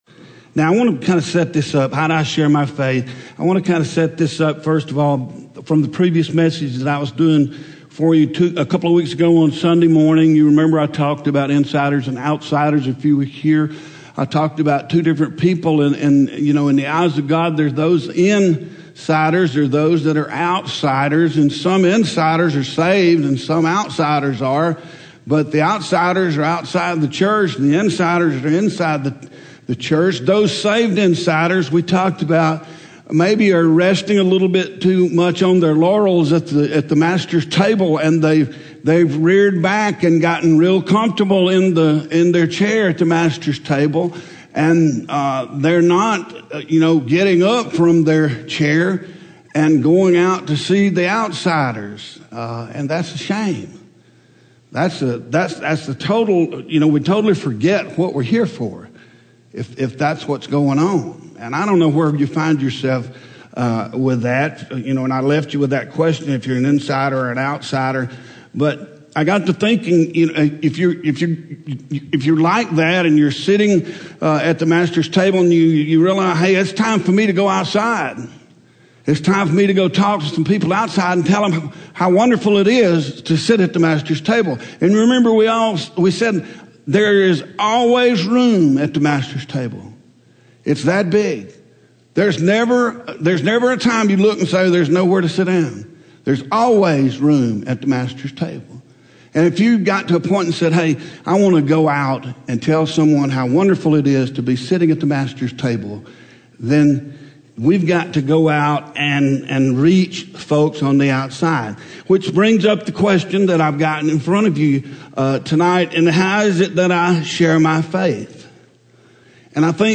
Home › Sermons › How Do I Share My Faith